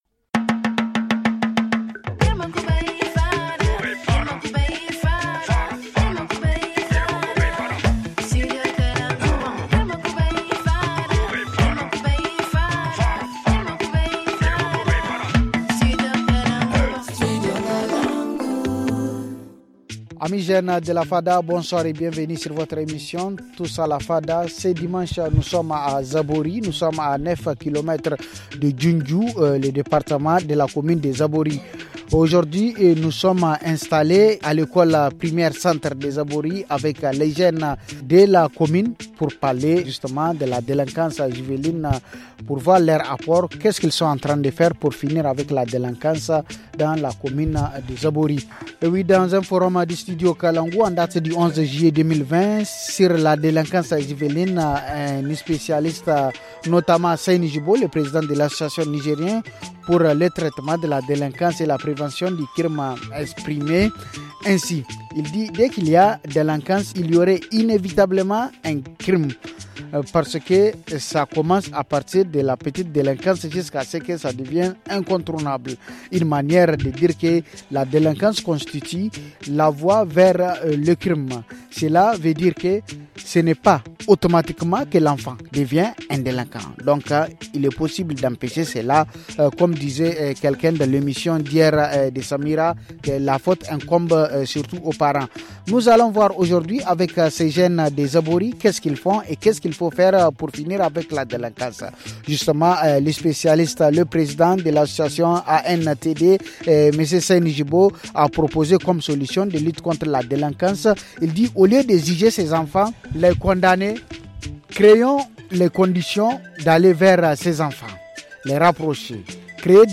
Ce soir, nous nous trouvons à la frontière entre le Niger et le Nigéria, aux côtés des jeunes, pour examiner leur contribution dans la lutte contre la délinquance juvénile.